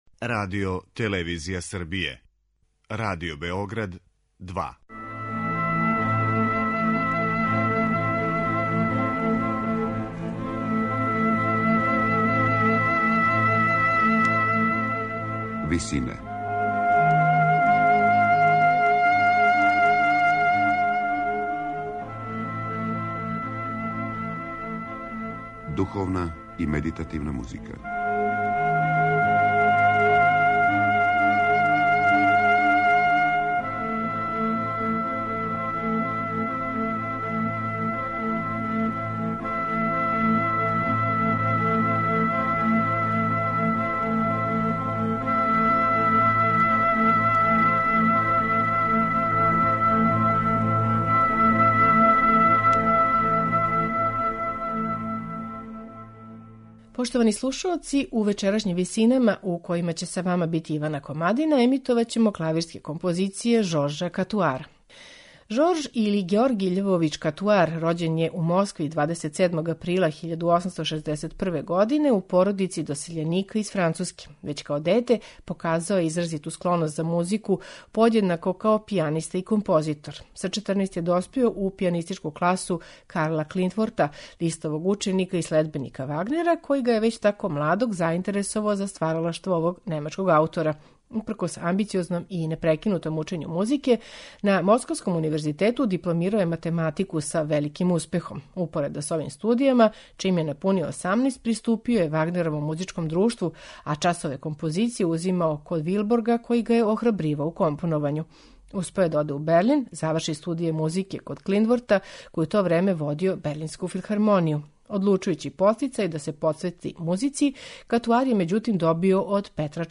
Жорж Катуар: Комади за клавир
На известан начин, ово усмерење се одразило и на дух Катуарове клавирске музике, која је, као и лирски комади Чајковског, изразито поетична: мада захтевна за пијанисте, има интимистички карактер и лишена је сваког спољног сјаја.
У вечерашњим Висинама слушаћемо композиције из четири збирке Каутарових клавирских комада и прелудијума, у интерпретацији пијанисте Марк-Андреа Амлена.